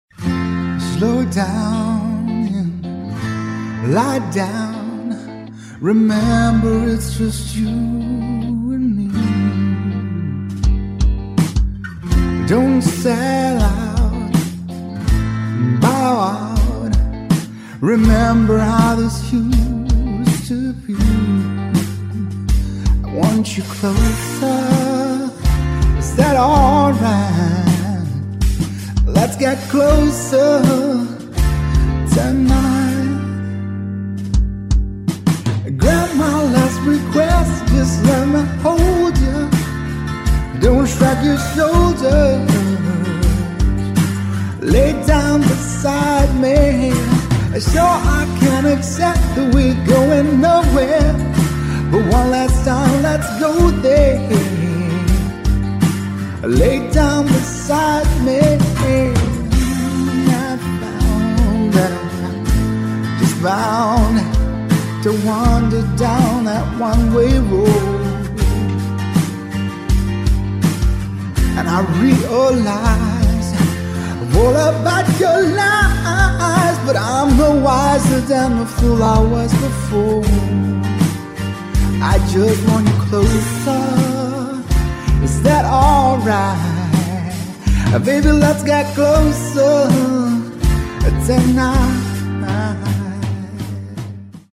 These are remastered live recordings from recent events.